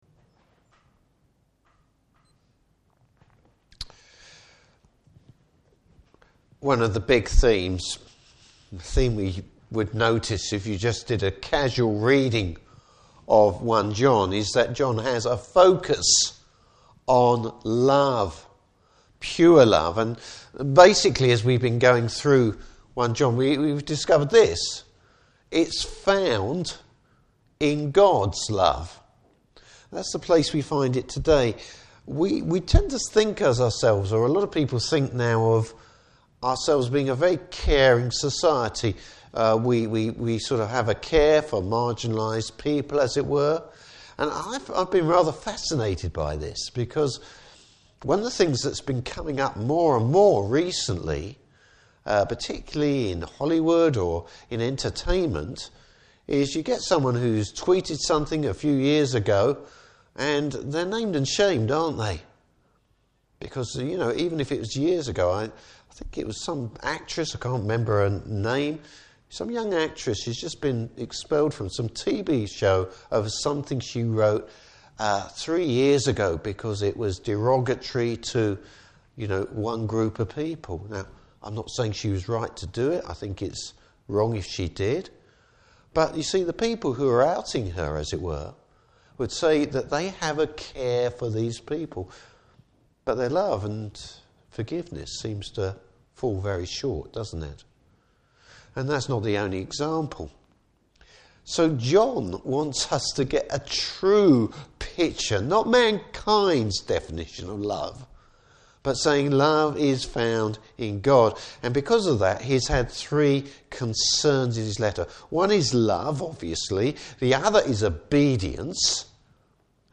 Service Type: Evening Service Bible Text: 1 John: 3:11-24.